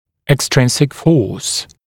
[ek’strɪnsɪk fɔːs] [ɪk-][эк’стринсик фо:с] [ик-]внешняя сила